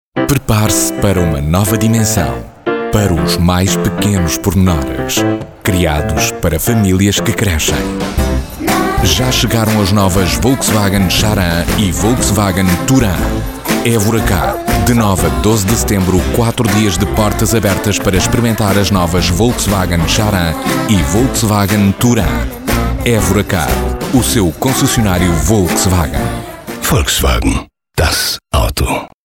Portuguese Voice Over.
Sprechprobe: Sonstiges (Muttersprache):